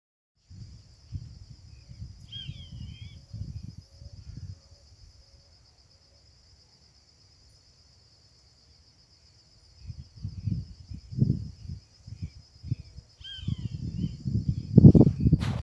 Roadside Hawk (Rupornis magnirostris)
Province / Department: Entre Ríos
Detailed location: Los Charrúas- Laguna San Bonifacio
Condition: Wild
Certainty: Observed, Recorded vocal